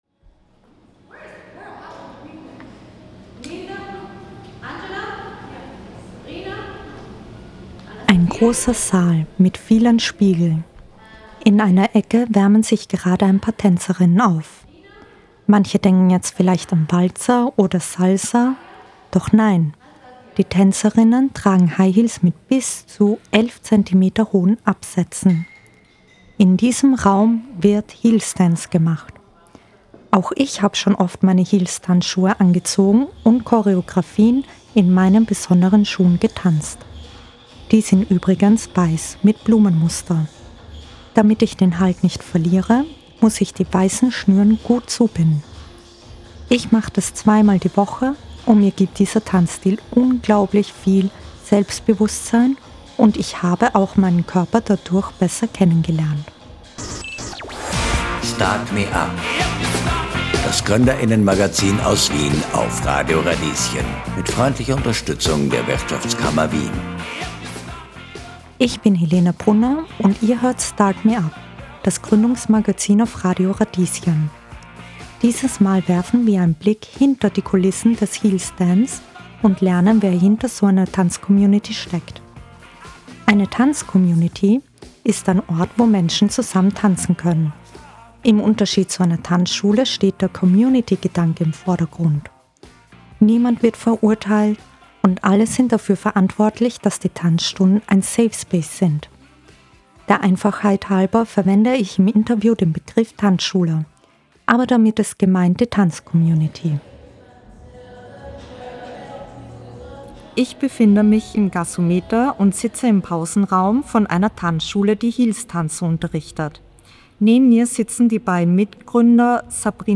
Beschreibung vor 3 Wochen Ein großer Saal mit vielen Spiegeln, Stimmengewirr - dann wird die Musik lauter und die ersten Klänge des rhythmischen Beats signalisieren: Es geht los.